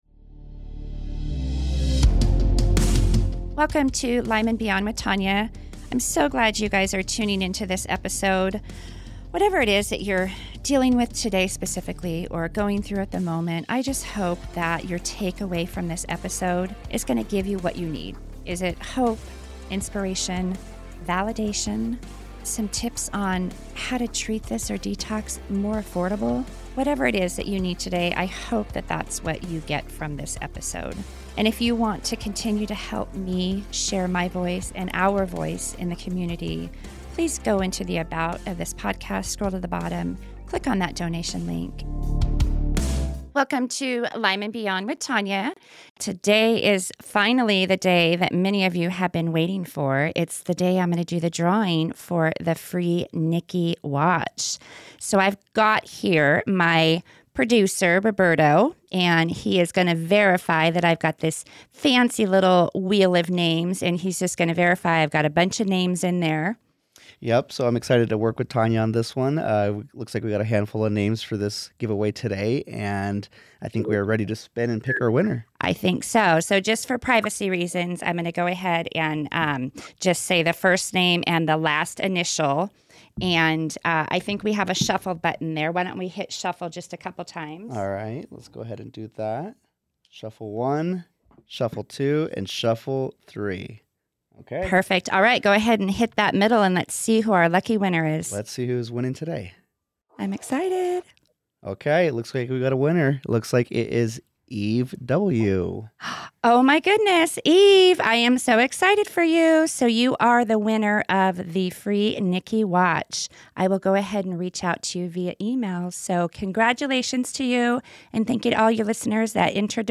This candid conversation highlights the importance of self-advocacy, mental resilience, and support within the Lyme community.